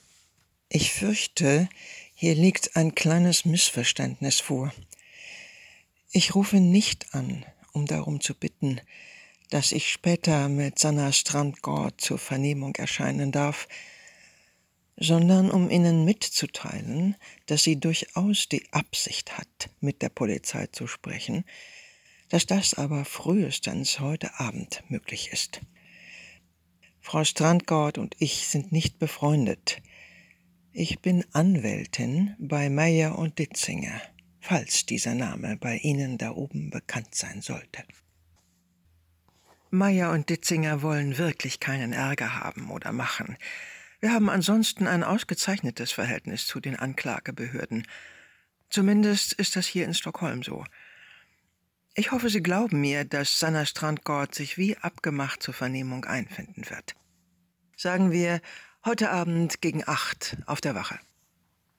markant
Alt (50-80)
Lip-Sync (Synchron), Scene